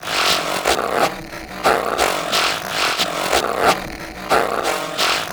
Index of /90_sSampleCDs/Spectrasonic Distorted Reality 2/Partition A/04 90-99 BPM